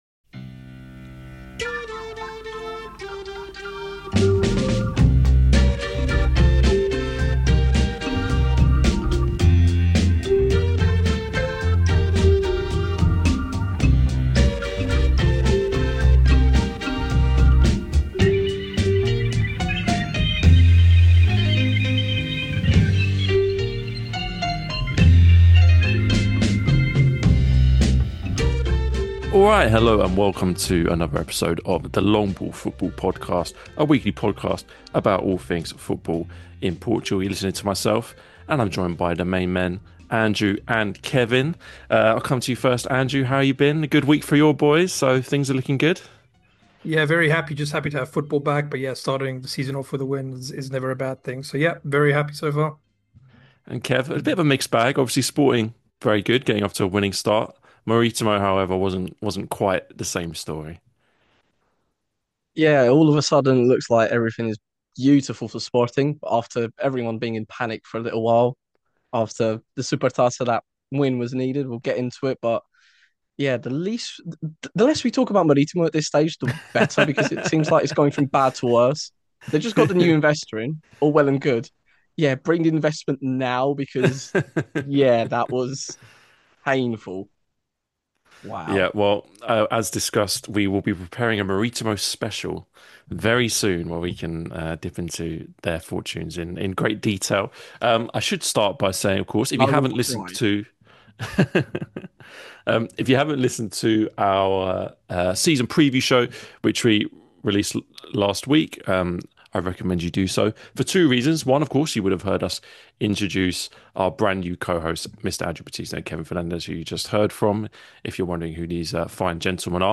A weekly podcast by two brothers about all things football in Portugal 🇵🇹⚽🇬🇧 Join us each week for Primeira Liga chat, and discussion about Portuguese clubs' exploits in Europe!